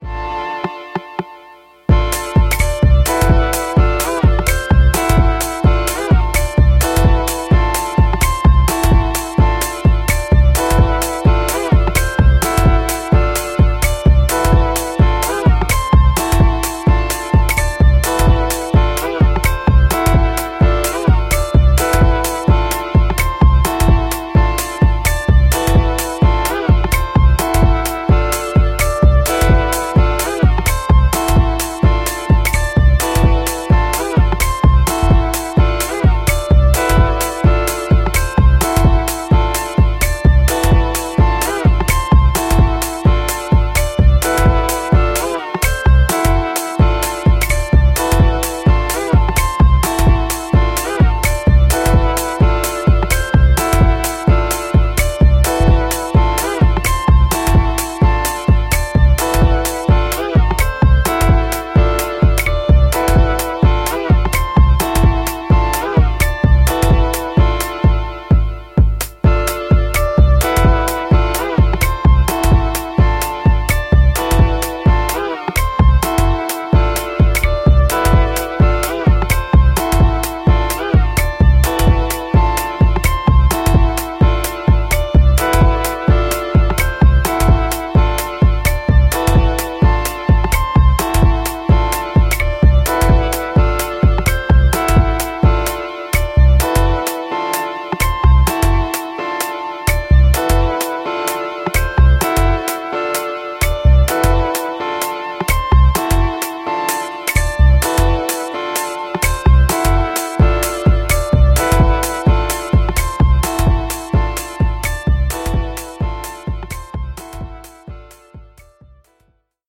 Raw and subtle techno.